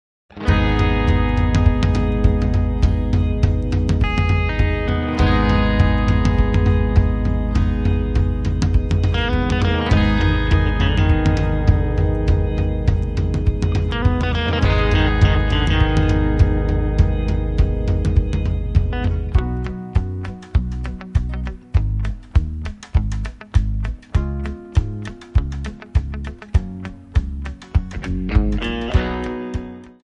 F#
MPEG 1 Layer 3 (Stereo)
Backing track Karaoke
Country, 2000s